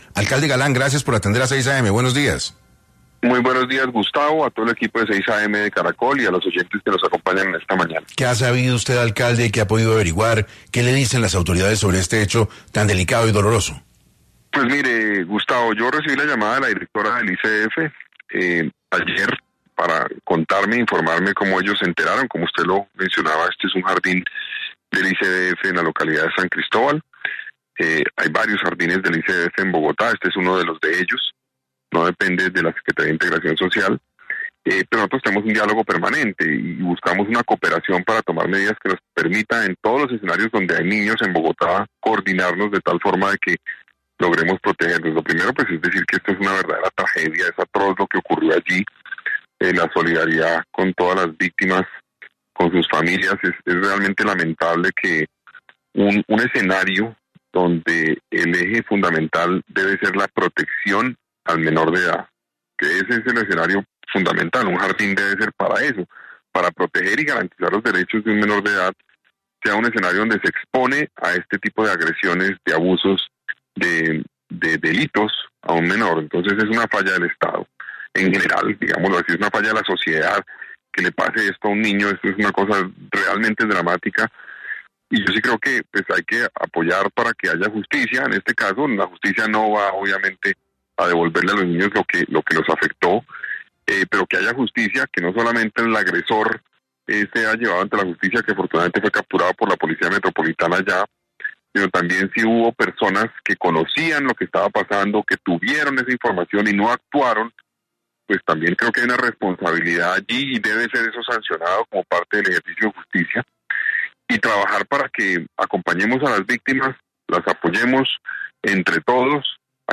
En diálogo con 6AM de Caracol Radio, el alcalde Carlos Fernando Galán rechazó los abusos a menores de edad y se solidarizó con las víctimas y sus familiares: